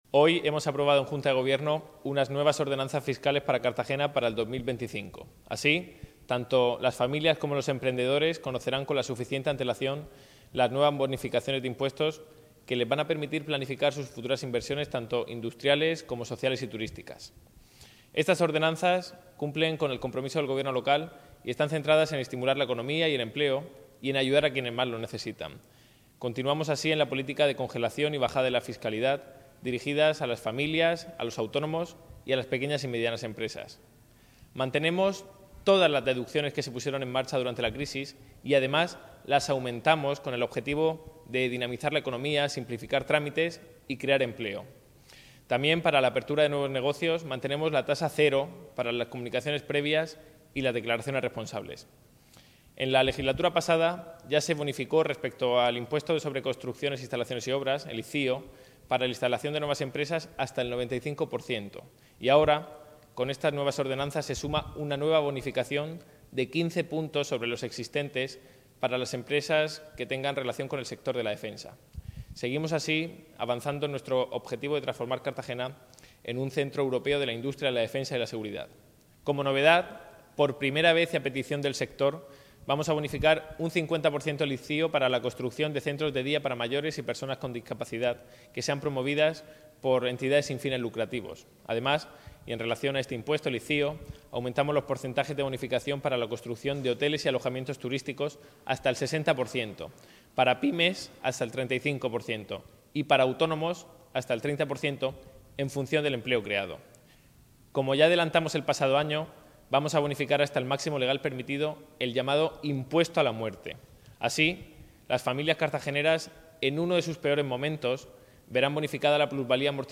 Enlace a Declaraciones del concejal Nacho Jáudenes.